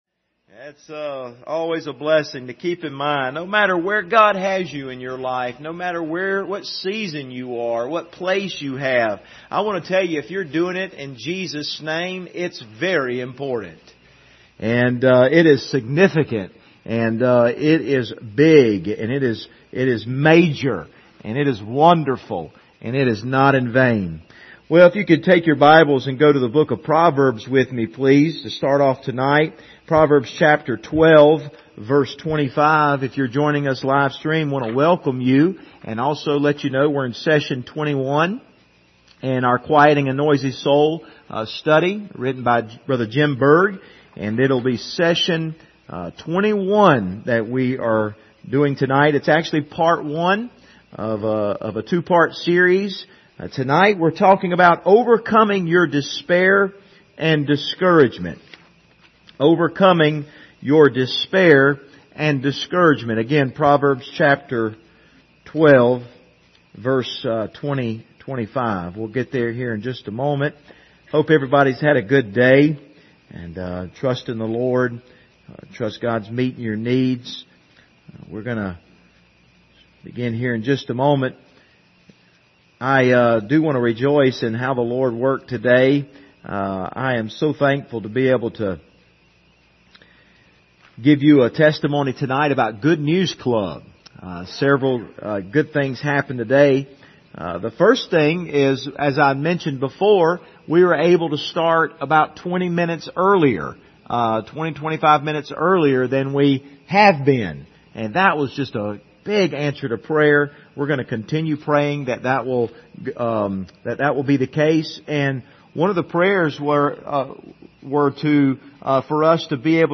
Service Type: Wednesday Evening Topics: depression , despair , discouragement , sorrow